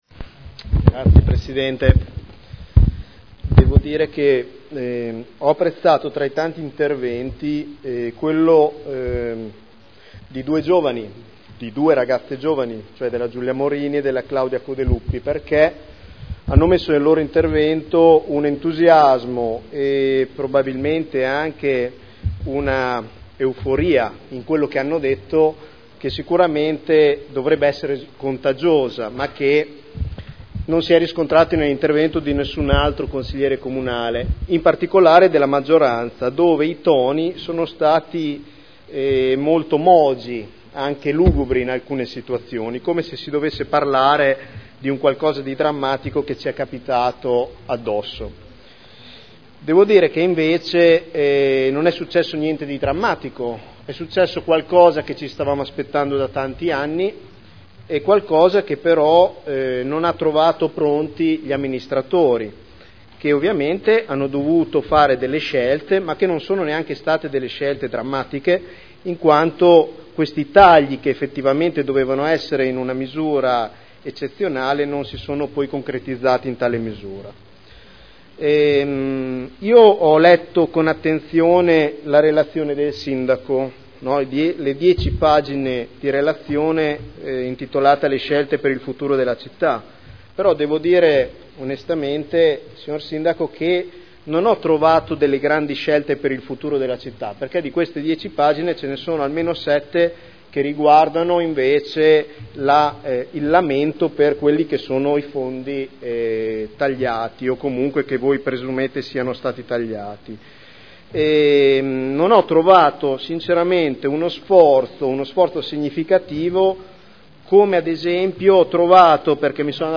Seduta del 28/03/2011. Dibattito sul Bilancio.
Audio Consiglio Comunale